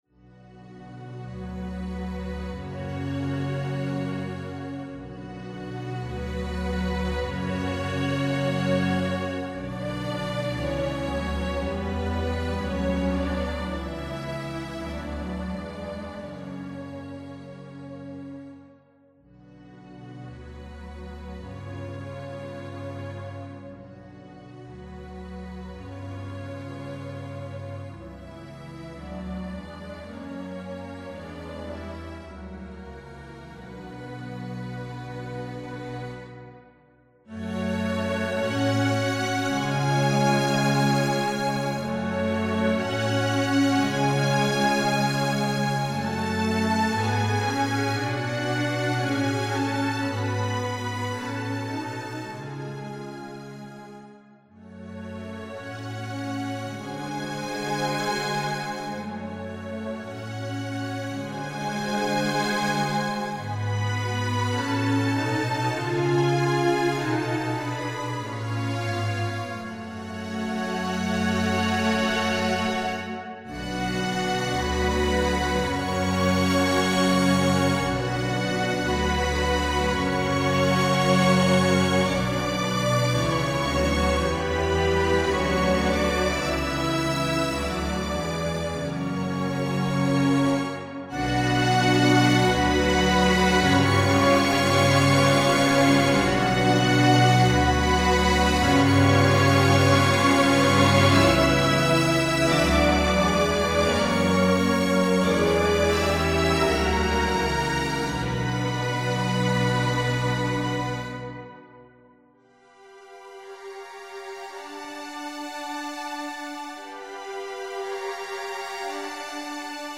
I’ve started out with some simple stuff, namely a string orchestra piece by Edward Grieg.
Very nice and judging by the screenshot you made loads of tempo changes in the project as well.